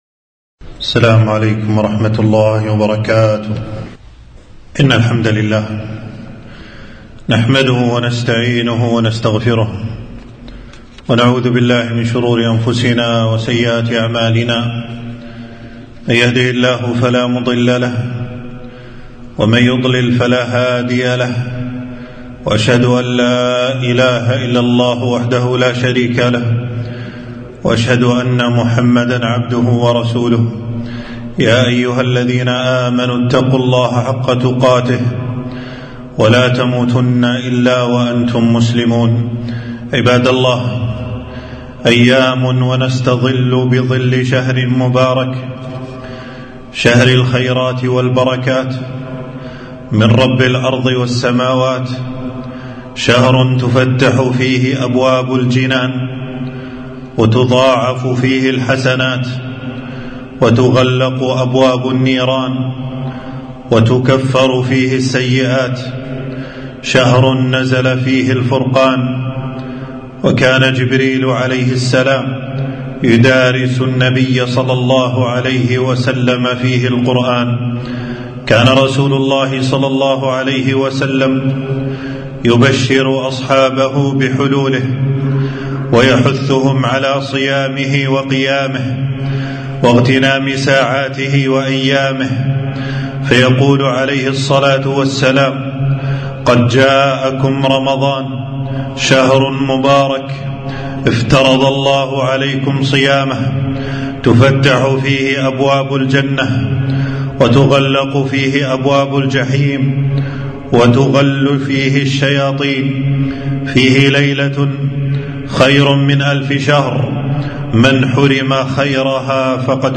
خطبة - قد جاءكم رمضان